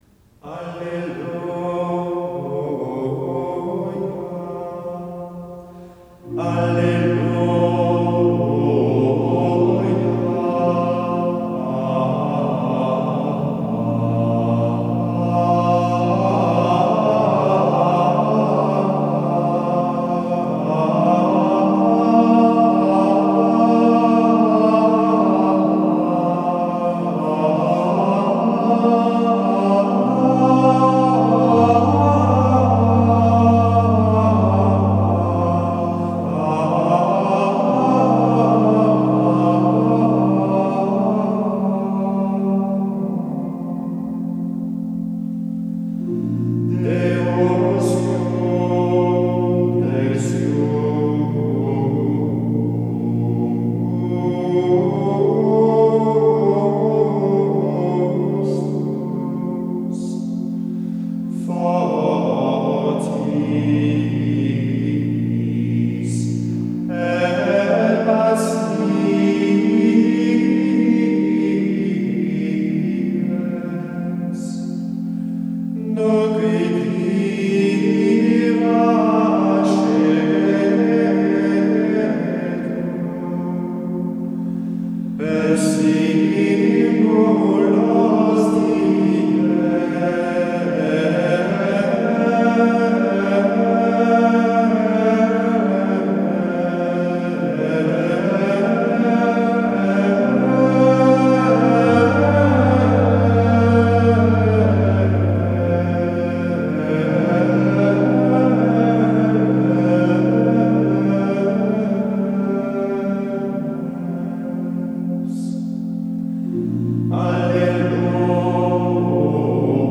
Extraordinary Form of the Roman Rite
03-alleluja-4.mp3